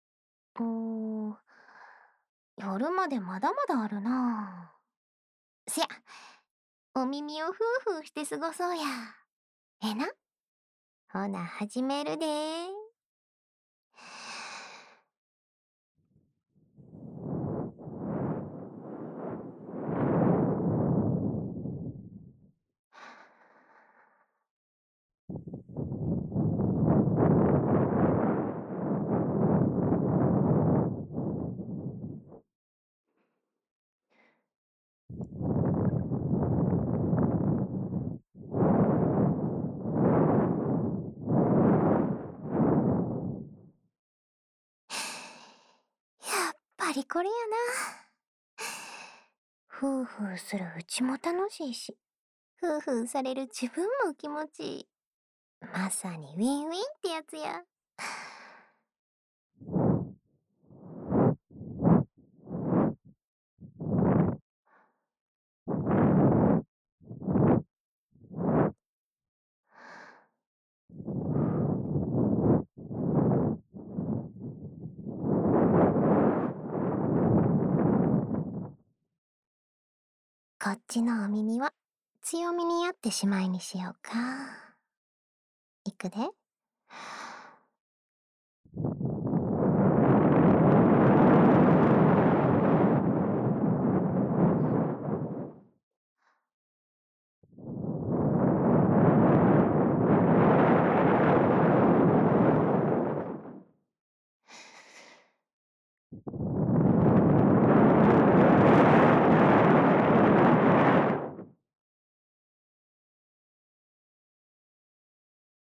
家中喝酒 ASMR
关西腔的同学